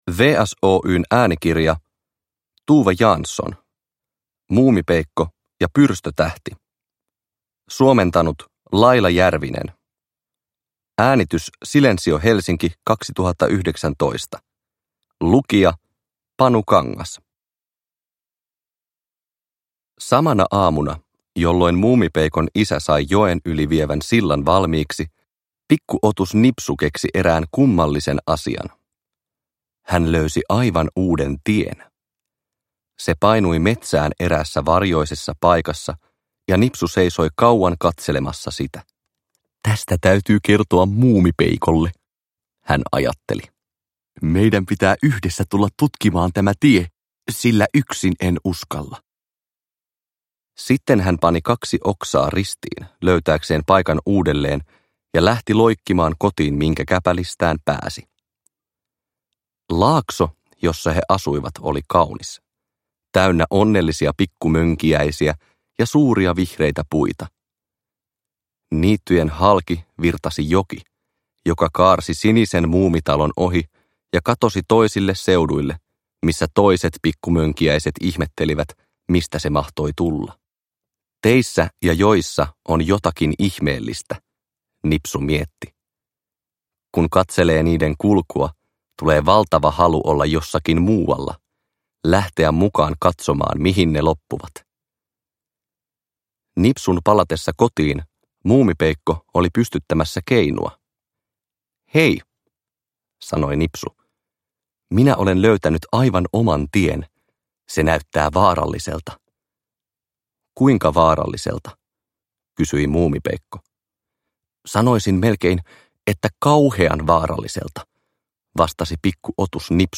Muumipeikko ja pyrstötähti (ljudbok) av Tove Jansson